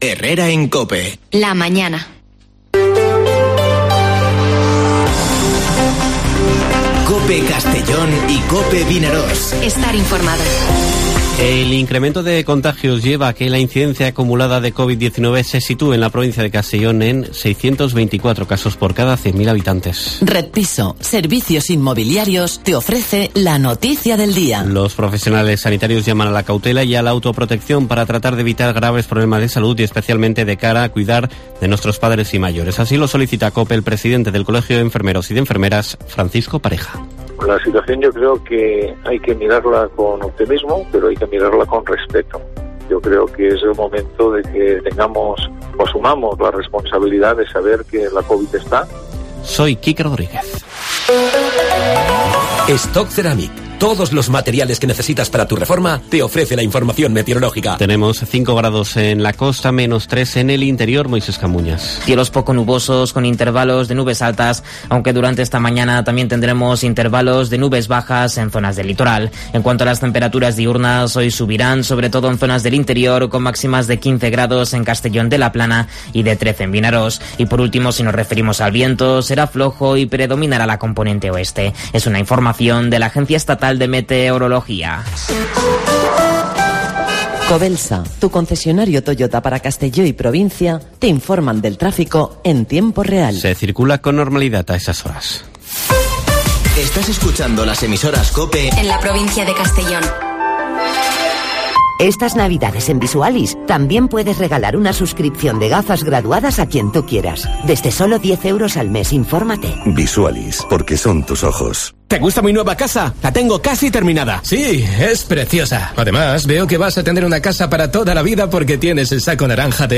Informativo Herrera en COPE en la provincia de Castellón (20/12/2021)